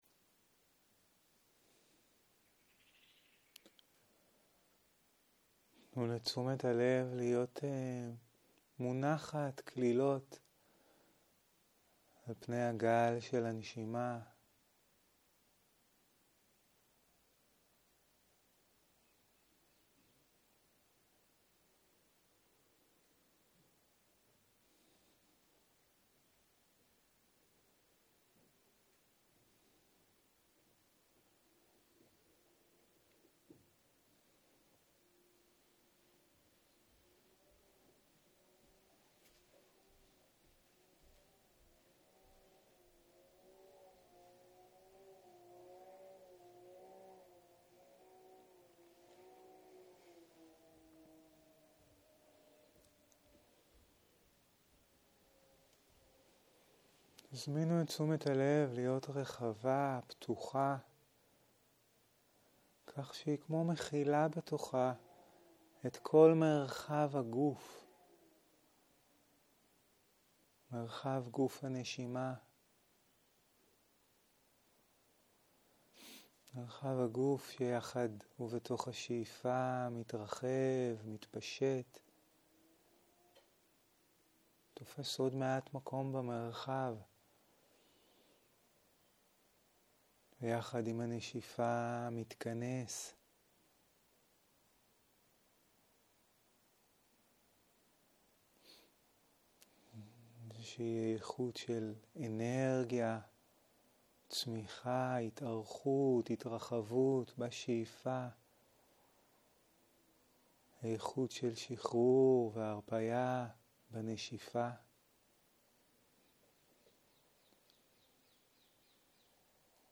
17.01.2023 - יום 5 - צהריים - מדיטציה מונחית - צלילים ומודעות פתוחה - הקלטה 10